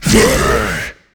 burer_attack_1.ogg